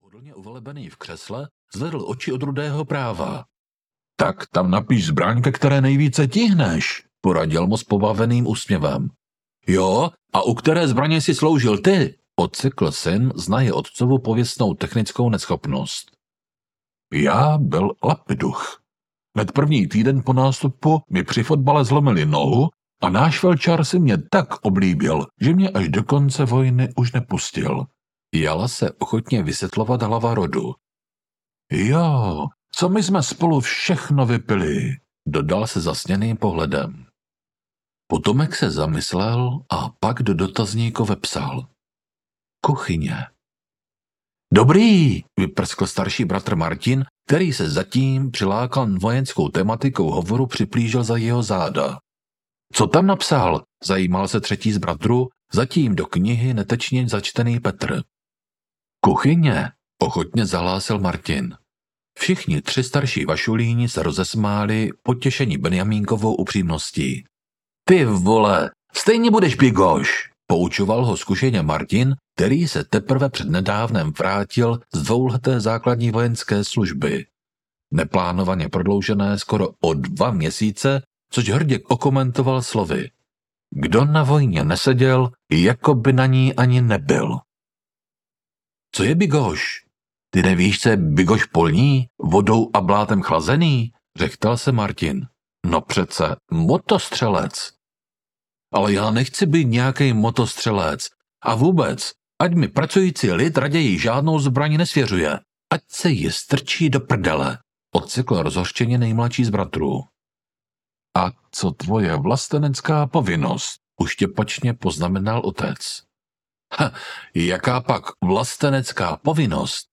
Černá rota audiokniha
Ukázka z knihy